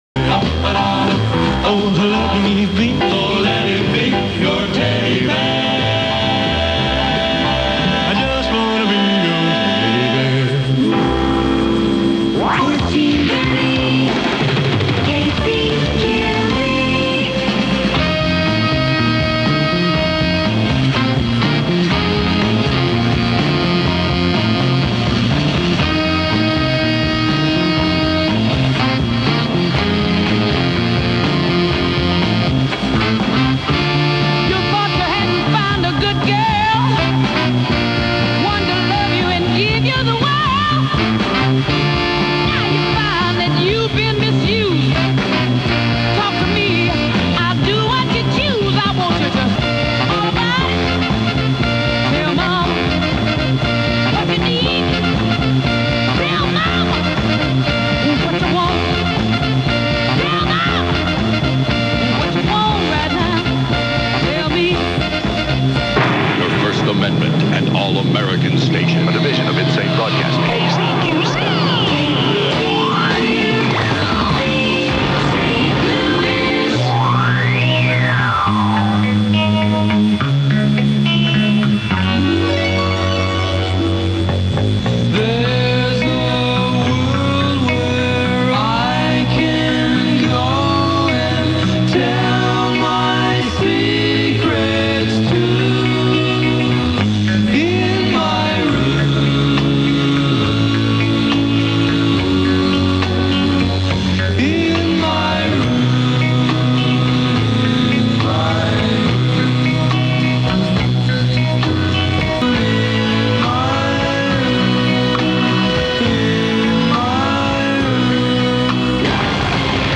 KZQZ Automated Aircheck · St. Louis Media History Archive